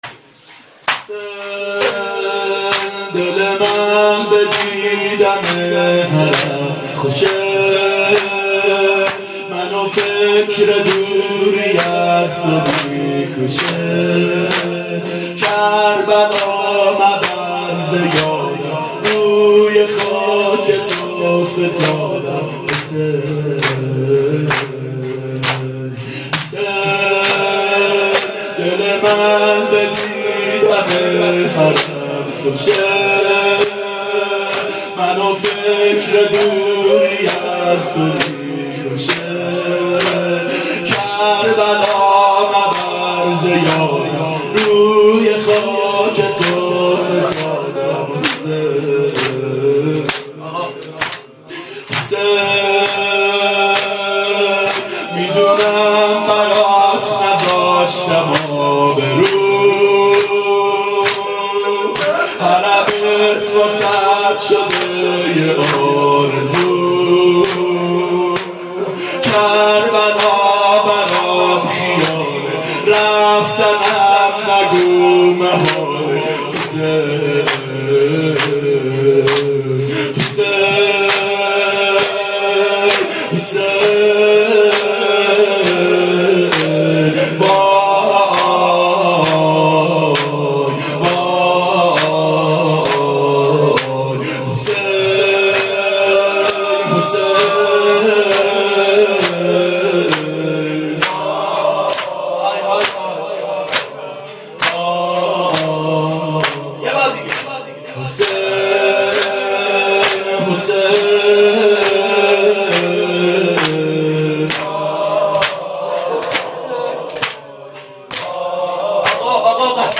• ظهر اربعین سال 1390 محفل شیفتگان حضرت رقیه سلام الله علیها